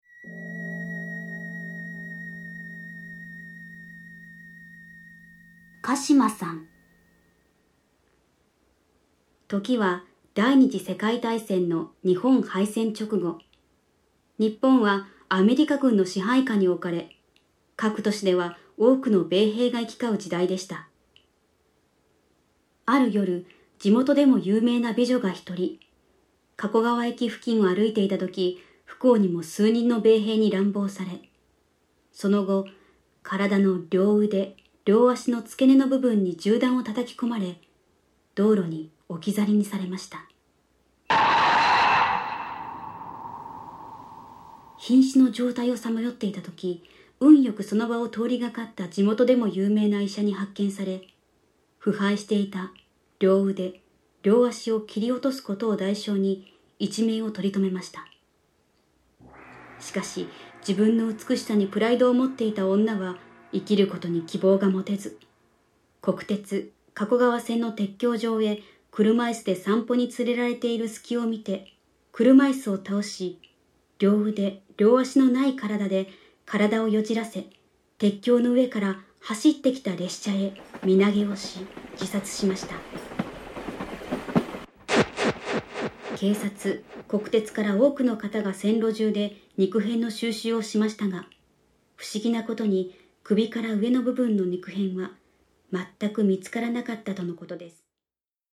SEにもこだわり、最先端技術を駆使し、擬似的に3D音響空間を再現、格別の臨場感を体感出来ます！